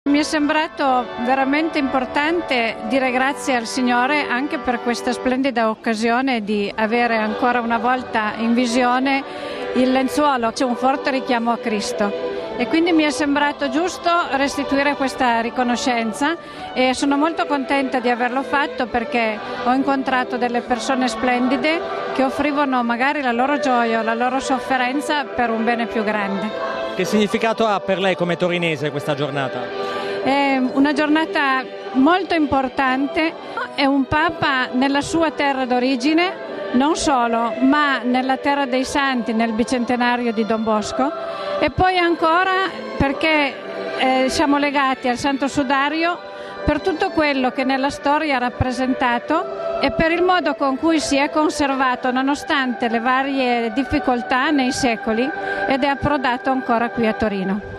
Una testimonianza di una volontaria